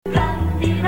1_2_man.mp3